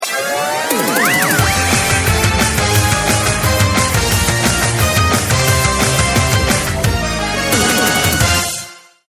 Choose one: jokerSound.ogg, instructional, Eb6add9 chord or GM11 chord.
jokerSound.ogg